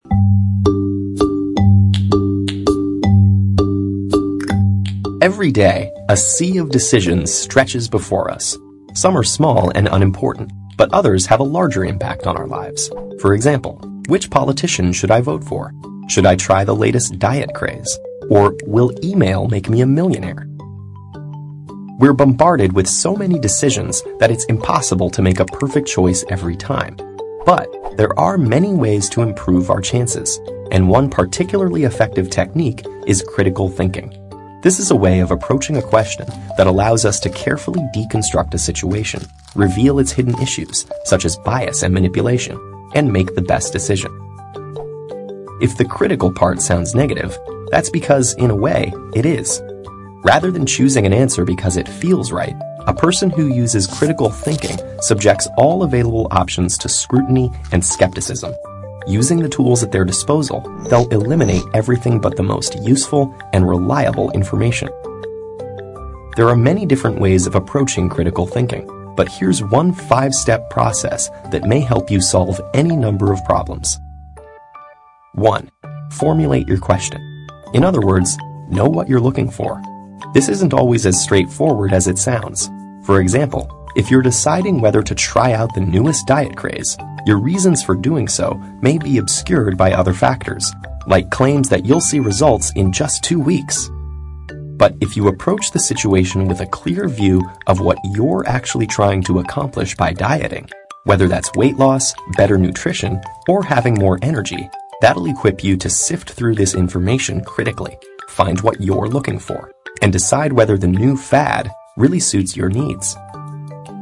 TED演讲:提高批判性思维的5个窍门(1) 听力文件下载—在线英语听力室